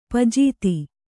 ♪ pajīti